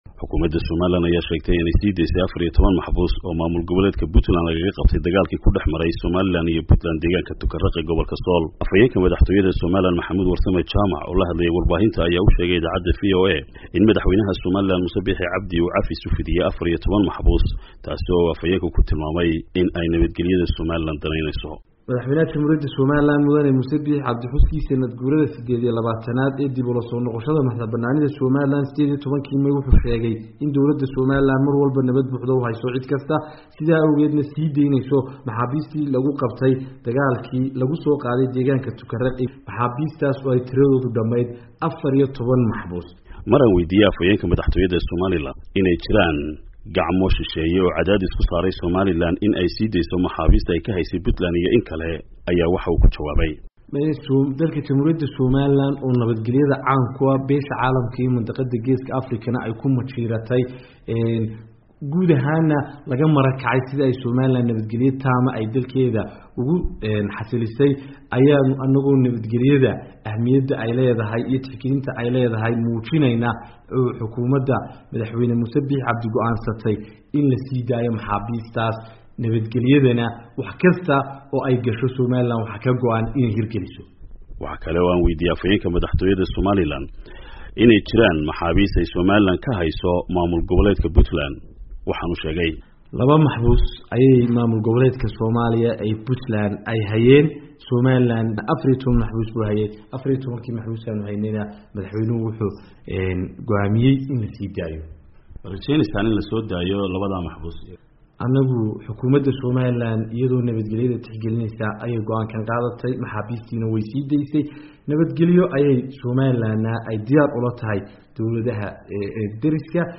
warbixintan nooga soo diray Hargaysa